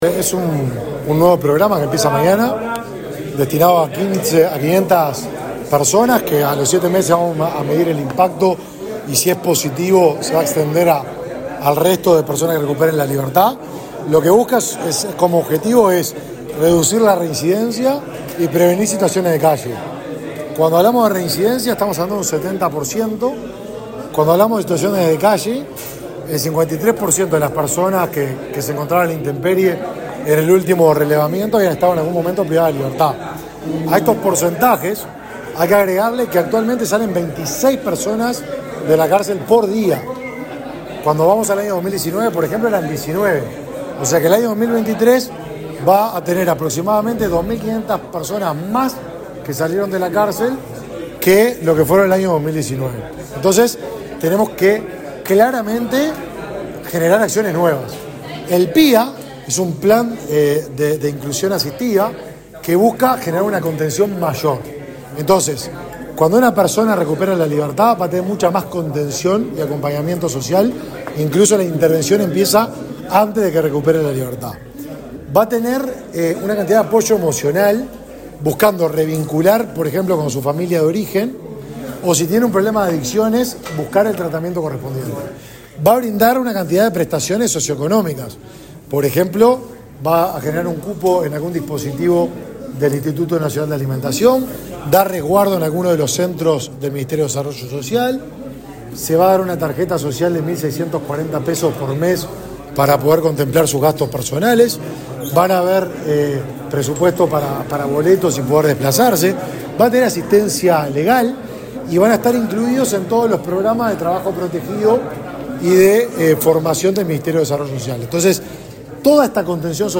Declaraciones del ministro de Desarrollo Social, Martín Lema
El ministro de Desarrollo Social, Martín Lema, dialogó con la prensa, luego de participar de la presentación del Proyecto de Inclusión Asistida (PIA).